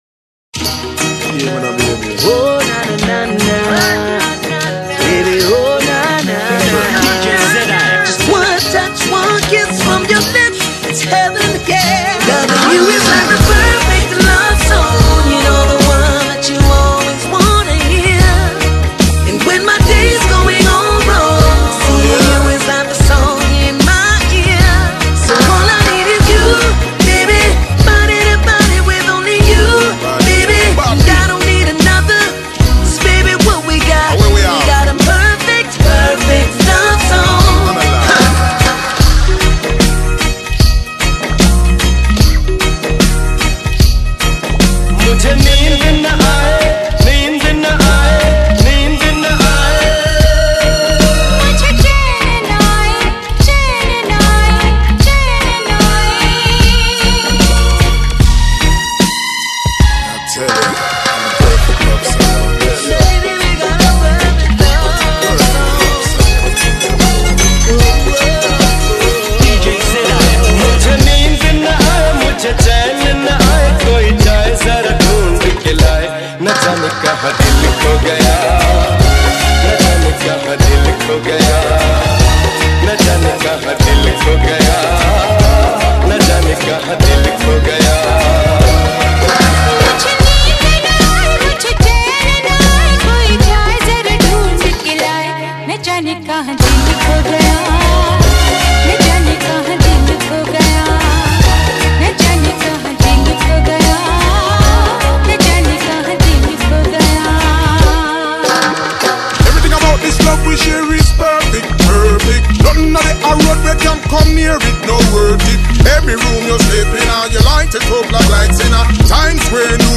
Hip Hop Remix